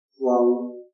口音（男声）